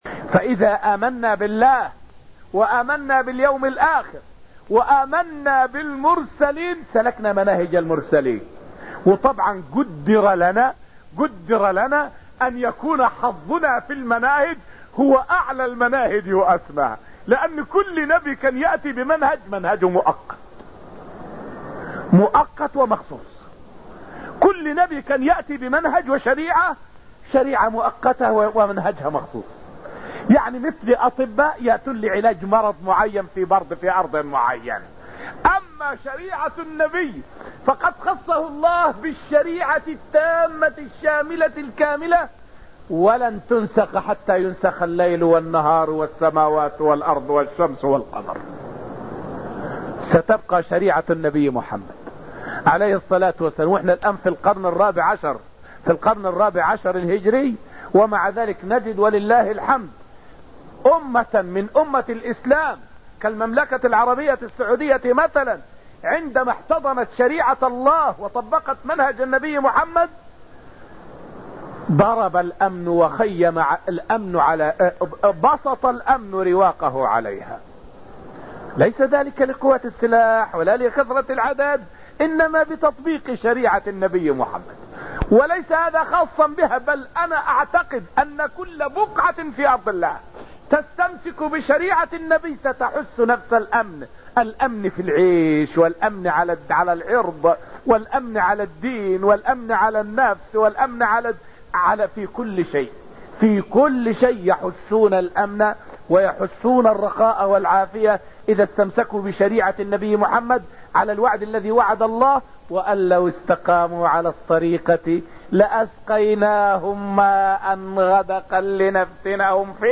فائدة من الدرس الثالث من دروس تفسير سورة القمر والتي ألقيت في المسجد النبوي الشريف حول الإيمان بالله تعالى سبيل الأمن والرخاء.